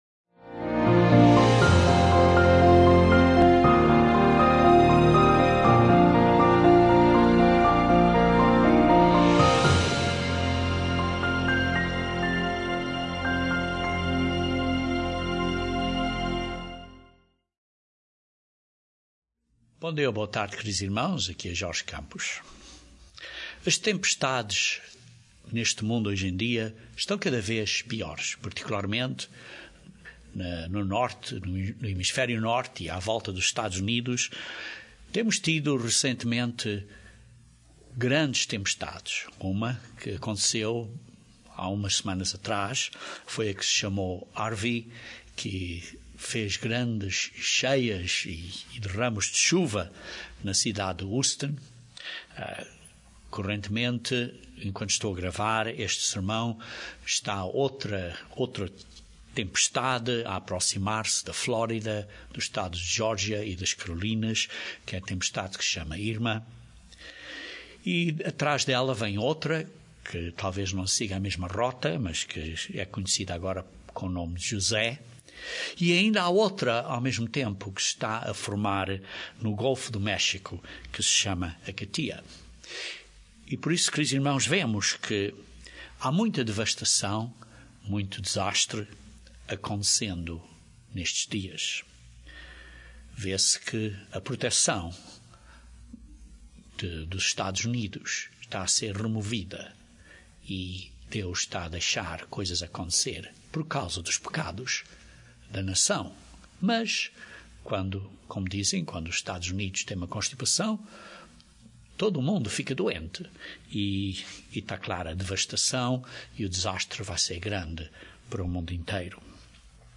Correntemente, enquanto estou a gravar, este sermão está a outra tempestade, a aproximar-se da Flórida, dos Estados de Jória e das Corolinas, que é a tempestade que se chama Irma.